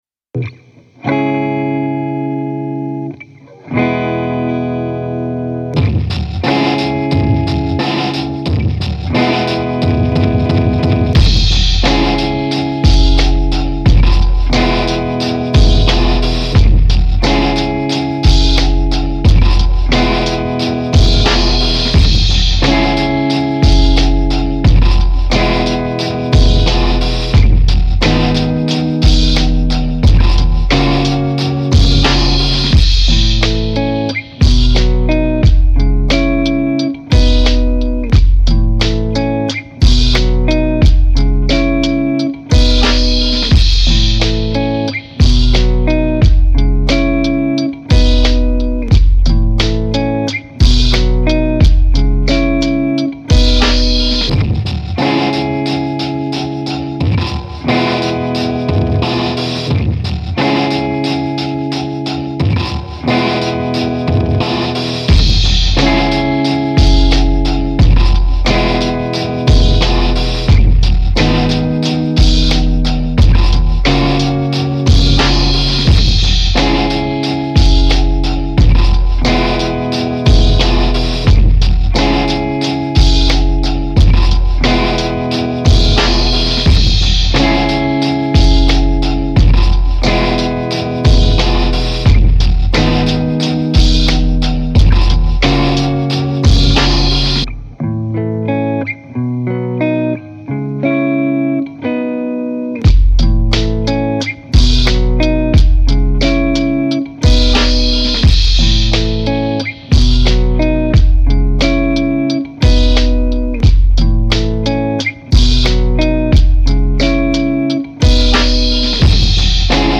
14 new instrumentals for you to rock over.